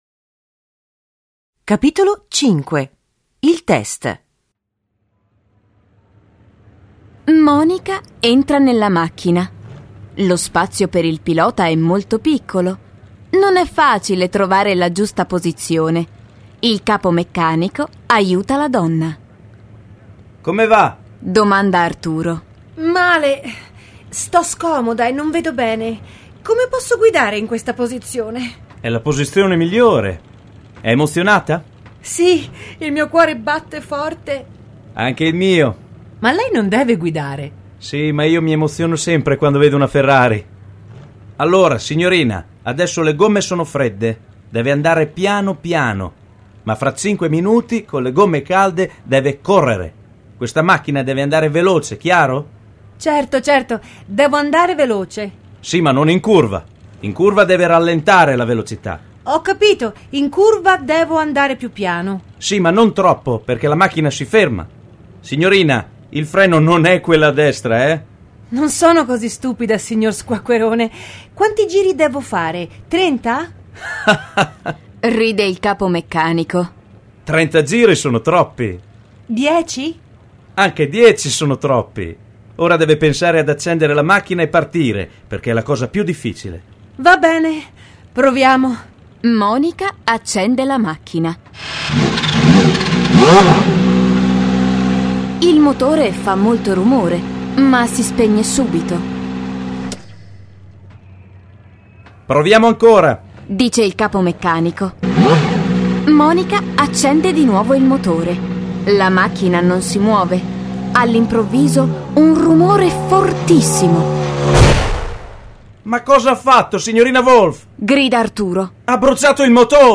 Il volume fa parte della Nuova Serie della collana Italiano Facile, letture graduate per studenti stranieri con esercizi e versione audio del testo, con voci di attori professionisti ed effetti sonori realistici e coinvolgenti.